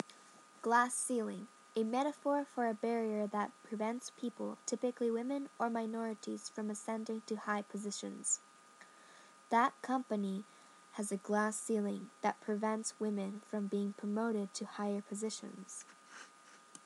英語ネイティブによる発音は下記のリンクから聞くことができます。